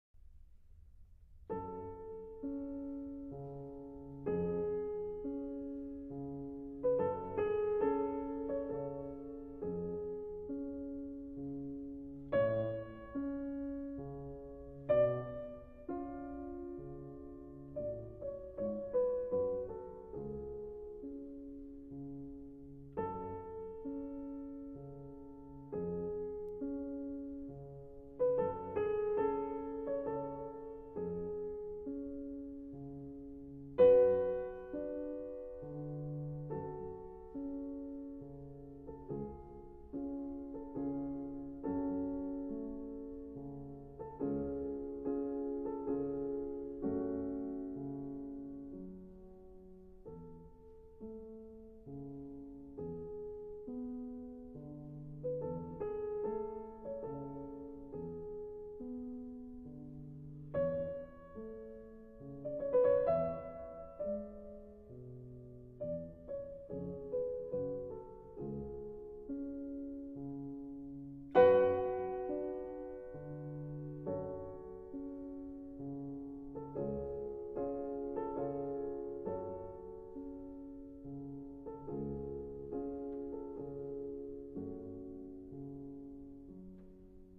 * Franz Schubert – Piano sonata D-959